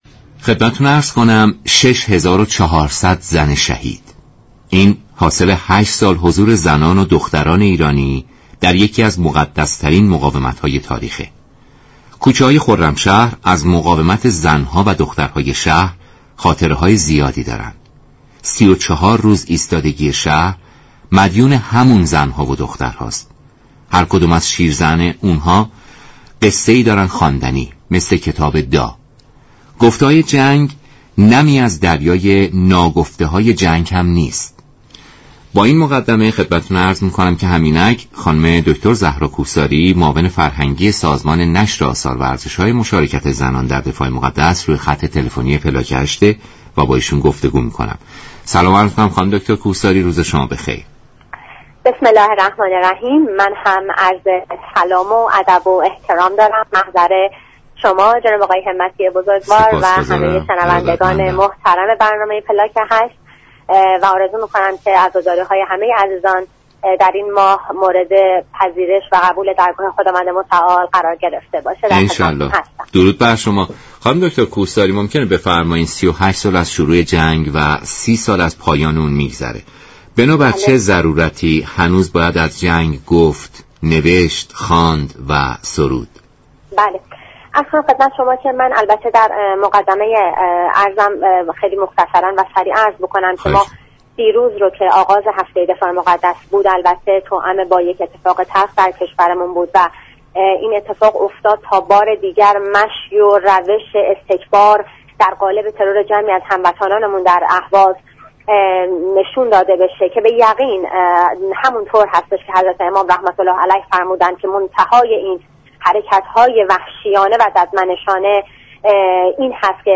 در گفت و گو با برنامه «پلاك 8» رادیو ایران